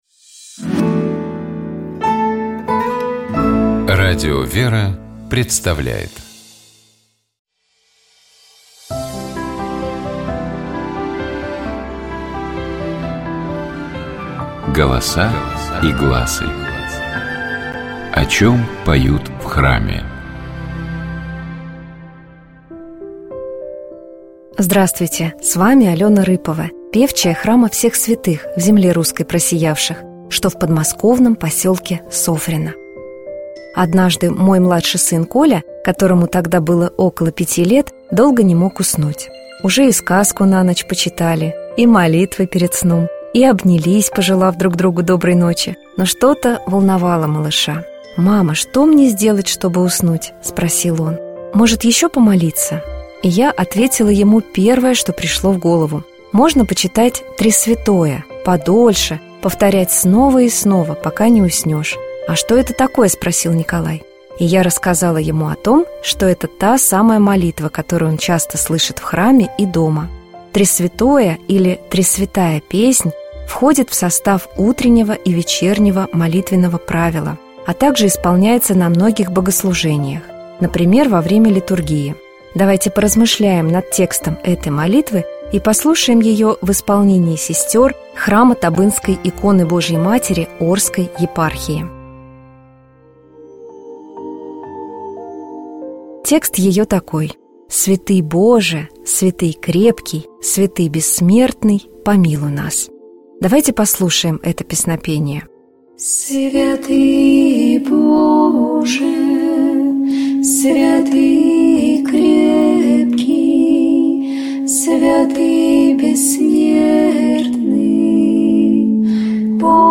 Давайте поразмышляем над текстом этого песнопения и послушаем его отдельными фрагментами в исполнении сестёр храма Табынской иконы Божией Матери Орской епархии.